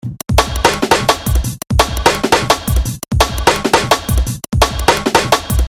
RPG女主角台词
描述：另一个论坛请求，为角色扮演游戏表达女性主角。
标签： 语音 RPG 主人公T 游戏 线条 人物 对话
声道立体声